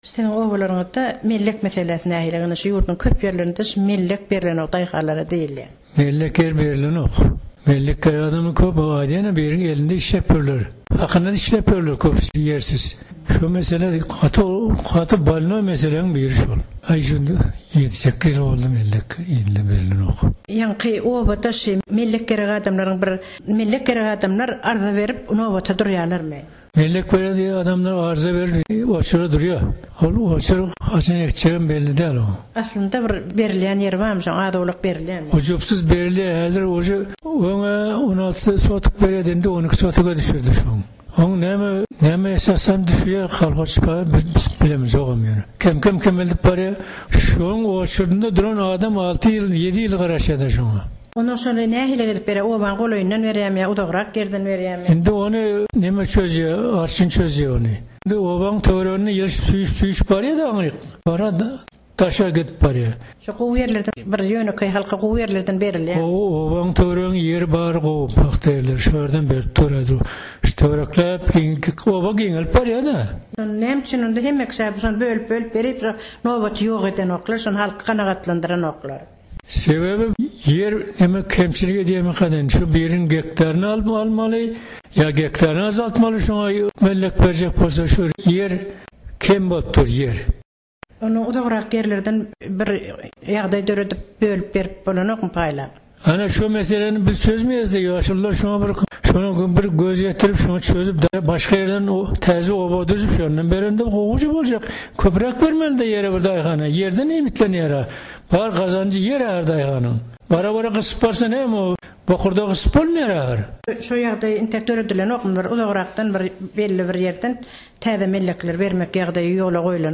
Oba ýaşaýjylary, Türkmenistan